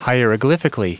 Prononciation du mot hieroglyphically en anglais (fichier audio)
Prononciation du mot : hieroglyphically